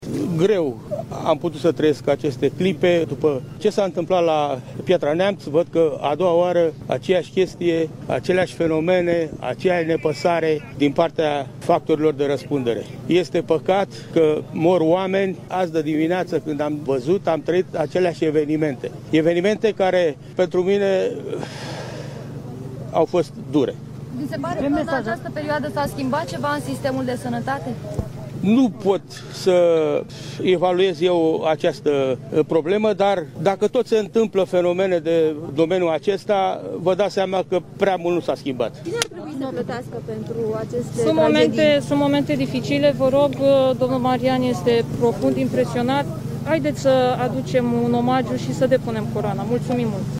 Un tata care si-a pierdut in incendiul de la Colectiv fiica și ginerele a venit la spitalul Matei Balș să depună o coroana de flori pentru victimele incendiului din această dimineață. Bărbatul spune că cele întâmplate i-au răscolit amintirile :